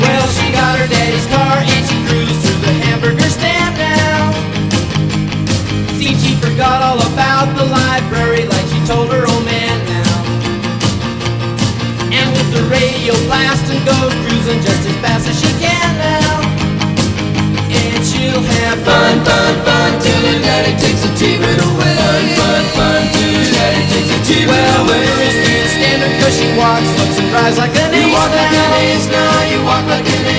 This is a sound sample from a commercial recording.
Reduced quality: Yes